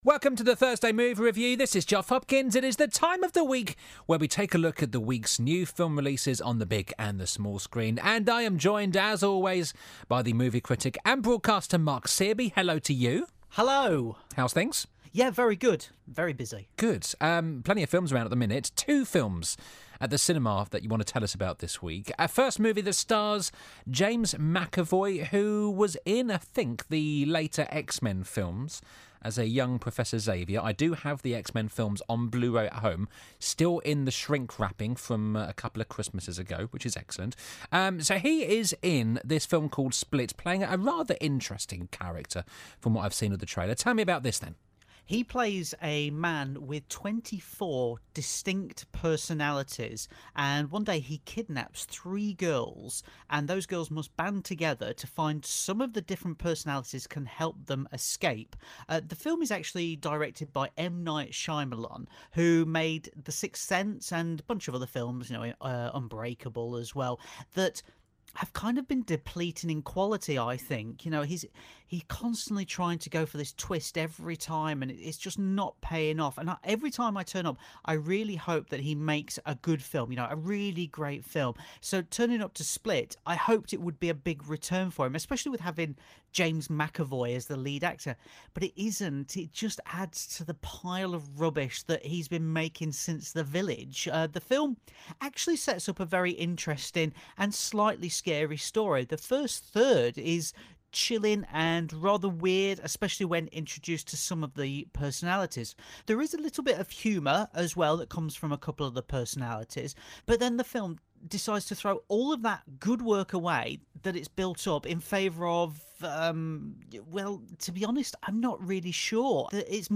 Director of The Sixth Sense, M Night Shyamalan returns with new film SPLIT, starring James McAvoy as a man with 24 distinct personalities. Find out if the movie divides opinion in our review.